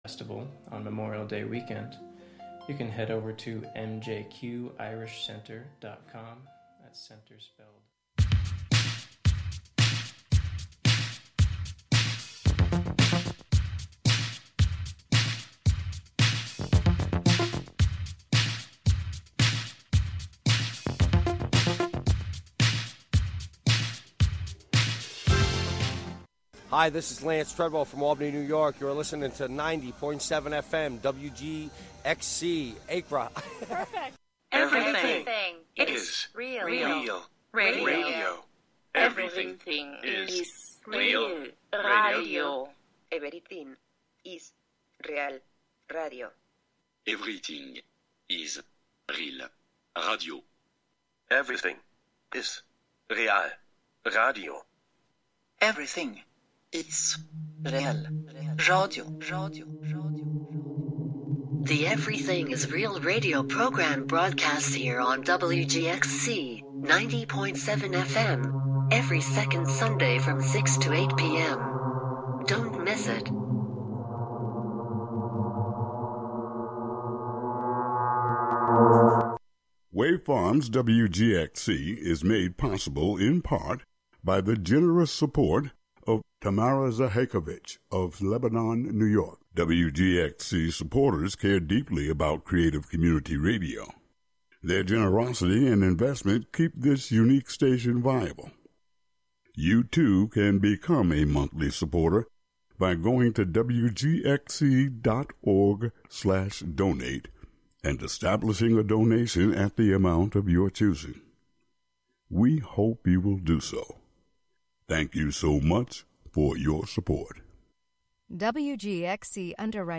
host lively conversation with community members, business owners, civic leaders, and more. Broadcasting live from either The Spark of Hudson , the Hudson Thursday Market , or other locations out and about in the community.